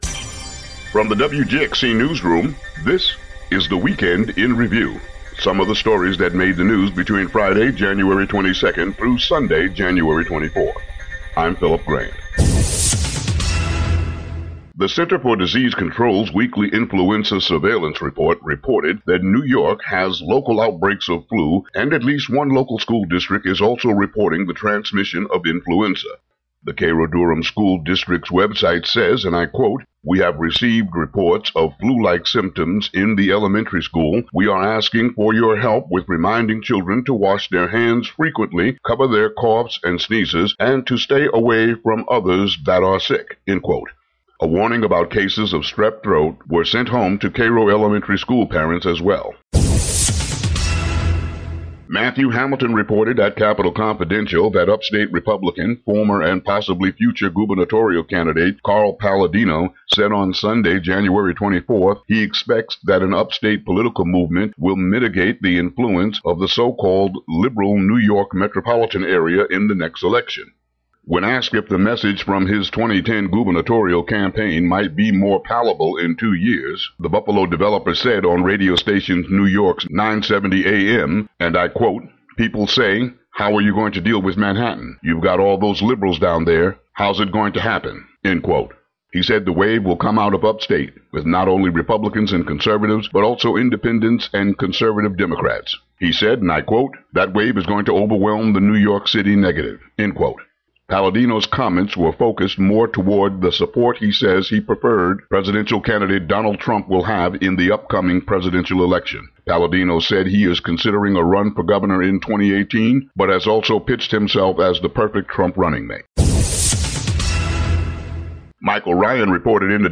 Local headlines and weather for Mon., Jan. 25.